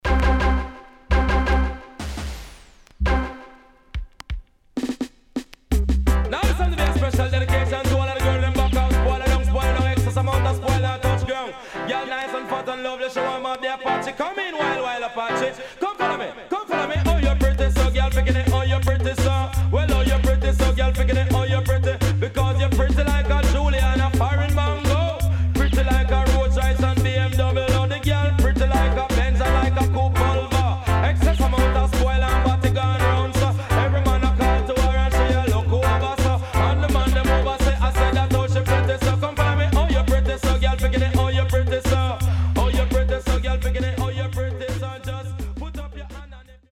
HOME > Back Order [DANCEHALL DISCO45]  >  COMBINATION
Side A:所々チリノイズがあり、少しプチノイズ入ります。